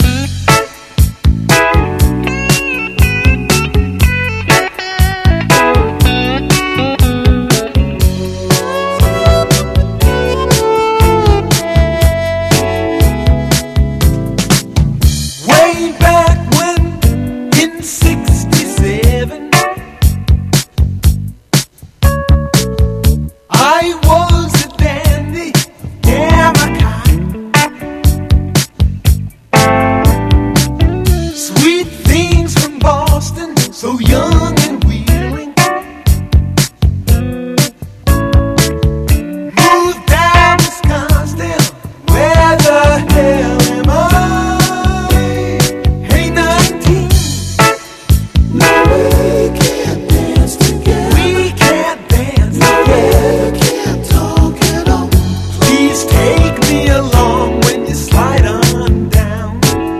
ROCK / S.S.W./A.O.R. / YACHT ROCK